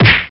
Âm thanh tiếng Cú Đấm